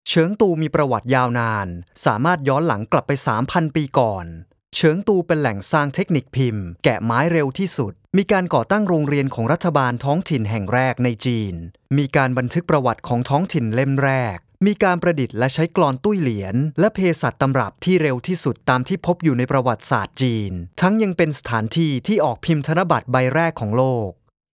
泰语样音试听下载
泰语配音员（男1） 泰语配音员（男2） 泰语配音员（男3）